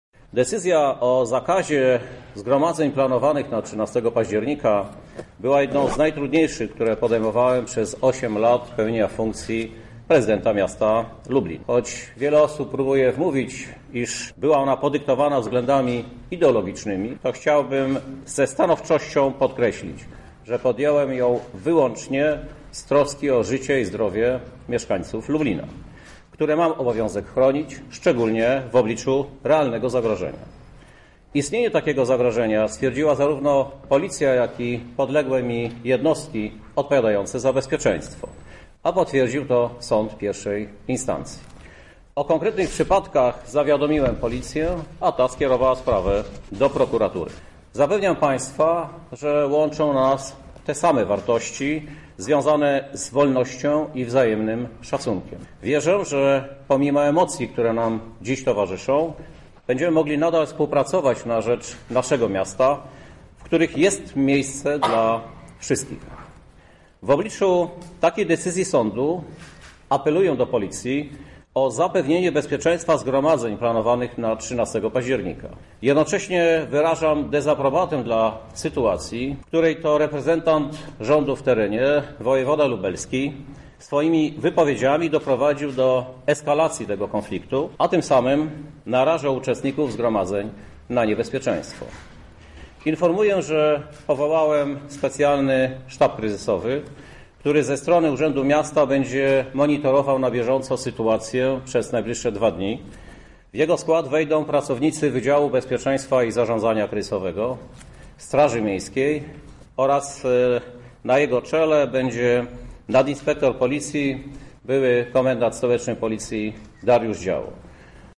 Godzinę po odczytaniu orzeczenia, Prezydent Krzysztof Żuk zorganizował briefing prasowy, na którym odniósł się do postanowienia sądu. Oświadczył, że wykona je tak jak wykonuje wszystkie prawomocne orzeczenia sądowe.